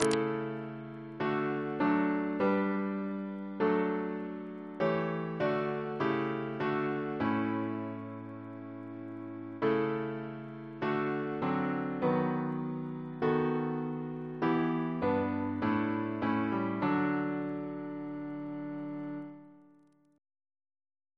Double chant in D Composer: Charles E. Miller (1856-1933) Reference psalters: ACB: 195